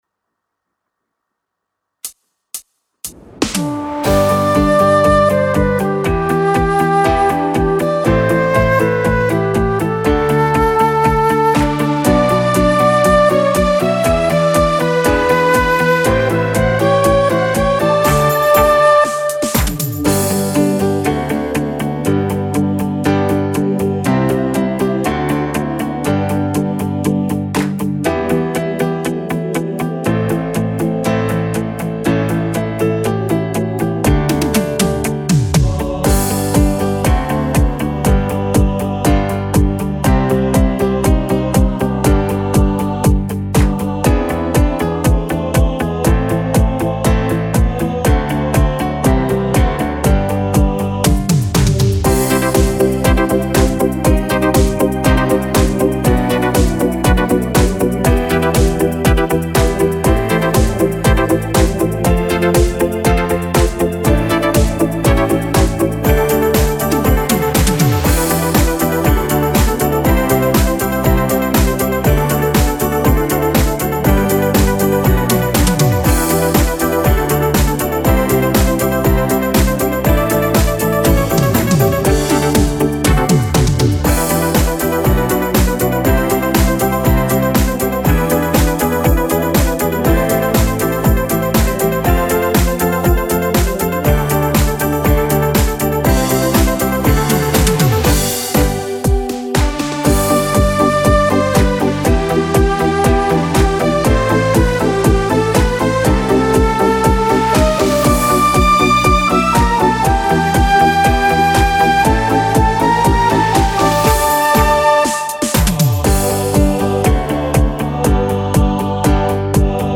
Tone Nam: G
•   Beat  01.